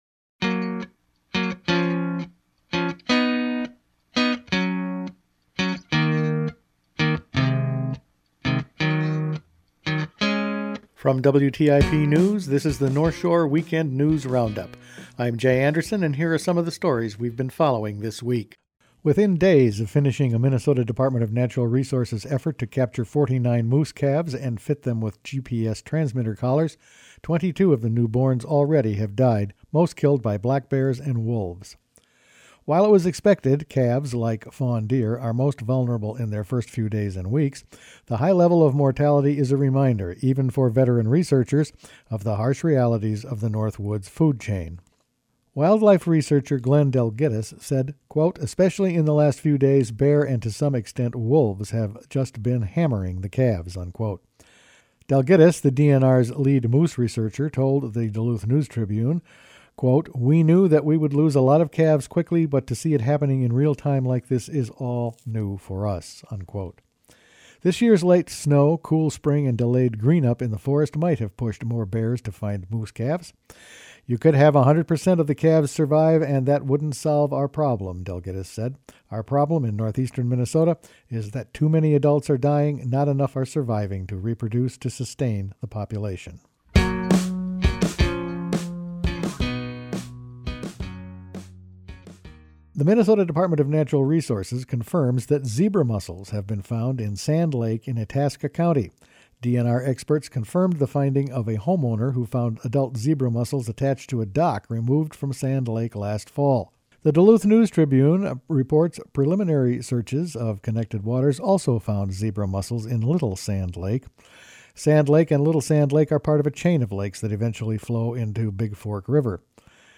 Each week the WTIP news staff puts together a roundup of the news over the past five days. High moose calf mortality concerns the DNR, so does finding zebra mussels in Itasca County. Missing boaters were found, wolf advocate lost a court decision.